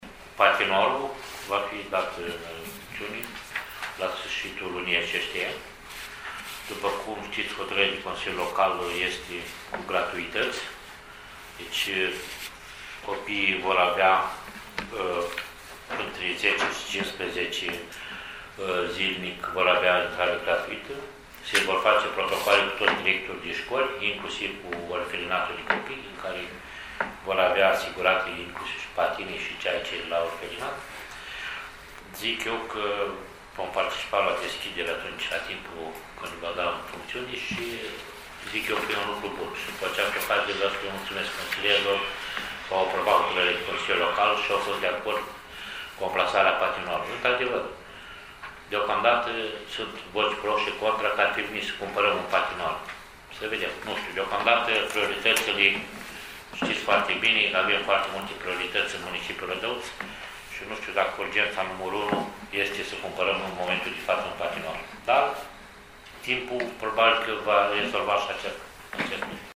Acesta se va deschide la sfârșitul lunii noiembrie 2017, după cum a precizat, astăzi, primarul municipiului Rădăuți, Nistor Tătar, într-o conferință de presă.